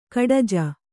♪ kaḍaja